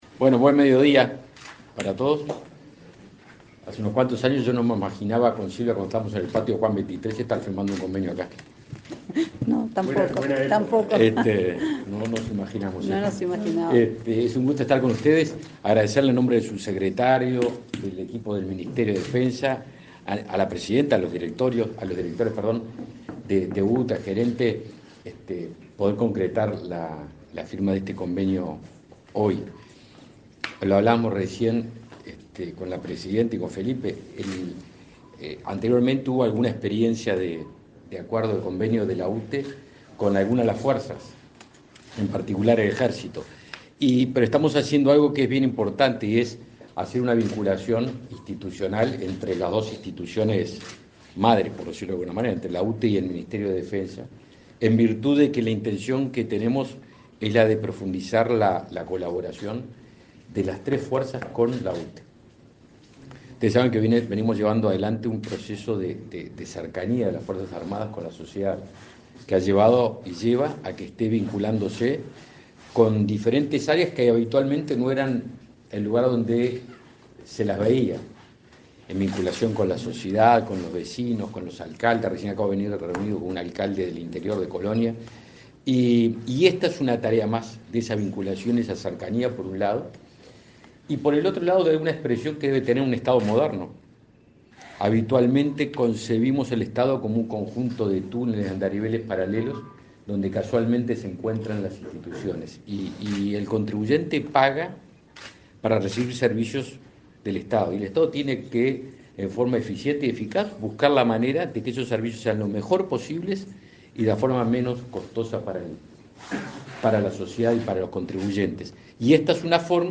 Conferencia de prensa por firma de convenio entre Ministerio de Defensa Nacional y UTE
Participaron el ministro Javier García y la presidenta de UTE, Silvia Emaldi.